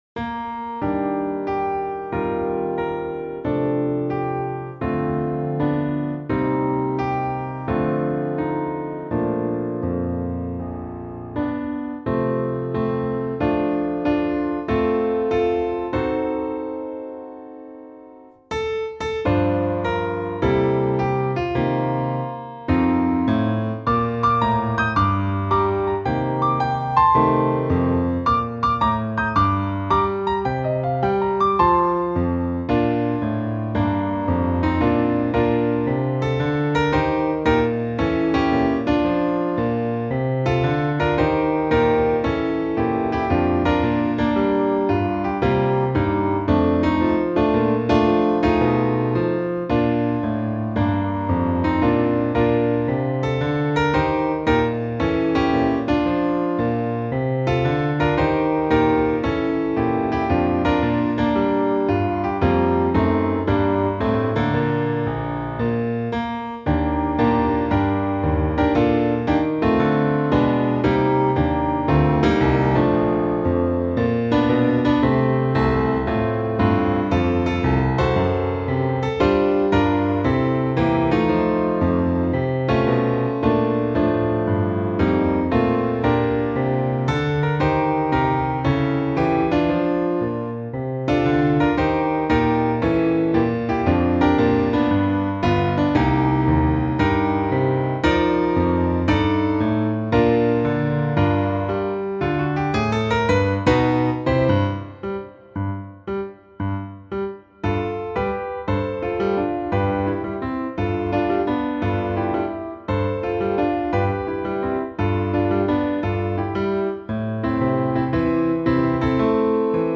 Intermediate Piano Solo
Song Sample